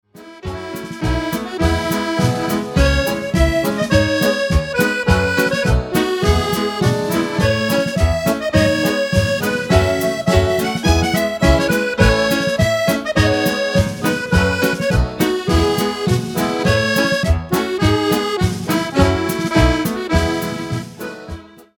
accordion
fiddle
drums